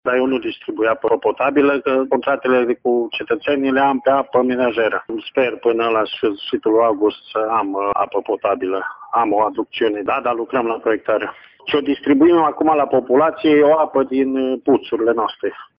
În aceeași situație se găsește și stația de apă din Valea Largă. Planuri de modernizare pentru această vară au și edilii de aici, dar, până atunci, apa va fi folosită strict pentru uz menajer, spune primarul comunei, Beniamin Pădurean: